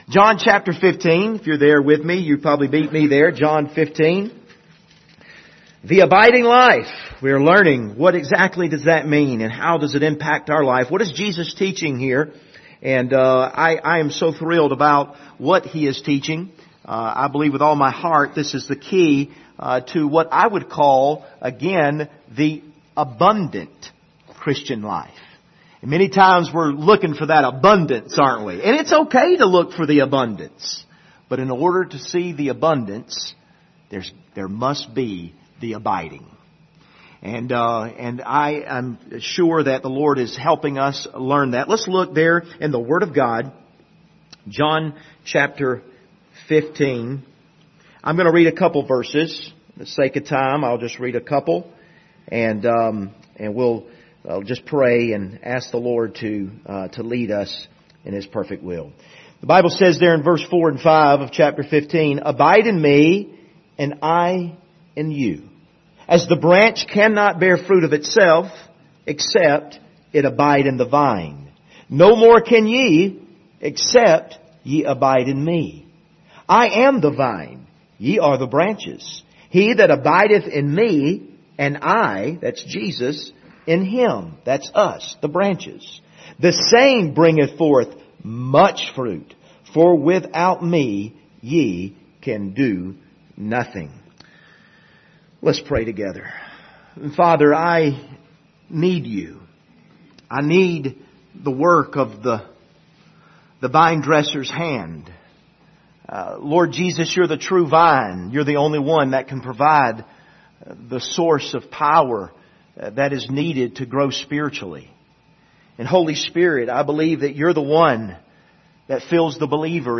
Service Type: Sunday Morning Topics: Compassion